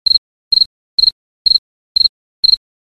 GRILLO MOLESTOSO
Tonos gratis para tu telefono – NUEVOS EFECTOS DE SONIDO DE AMBIENTE de GRILLO MOLESTOSO
Ambient sound effects
grillo_molestoso.mp3